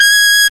Index of /m8-backup/M8/Samples/FAIRLIGHT CMI IIX/BRASS1